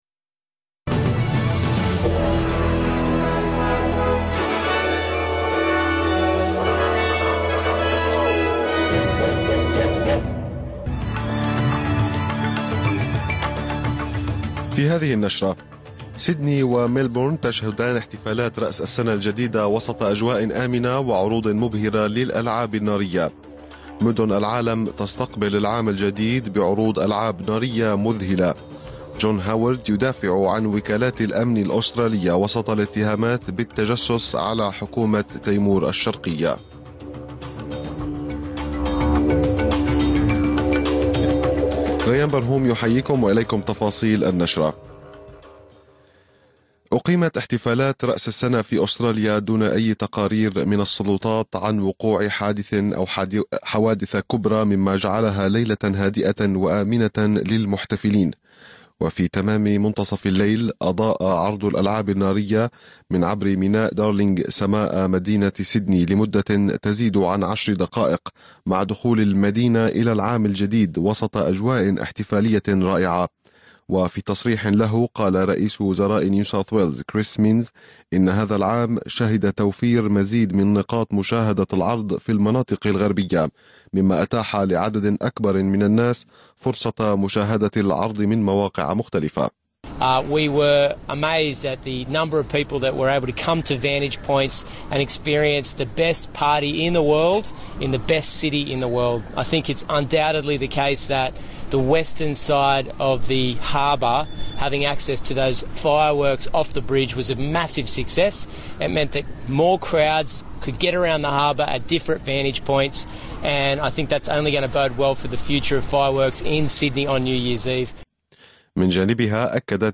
نشرة أخبار المساء 1/1/2024